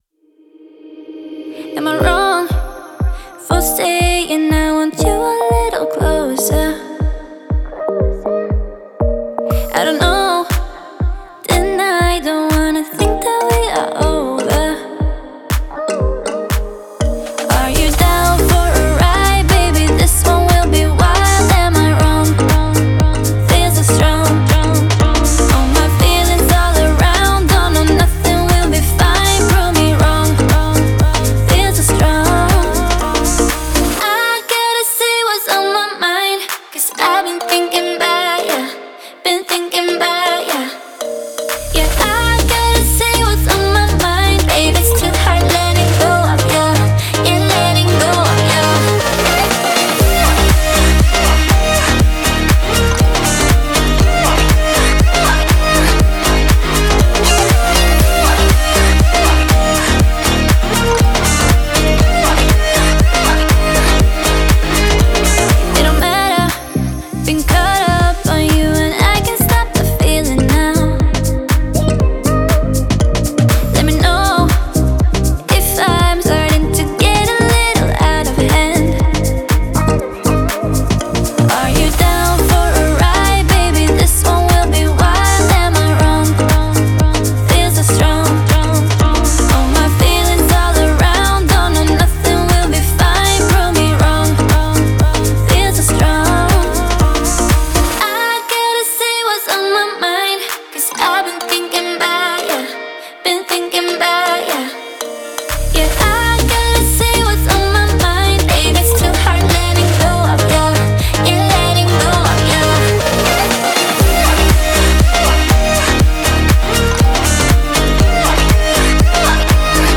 это мощная композиция в жанре хип-хоп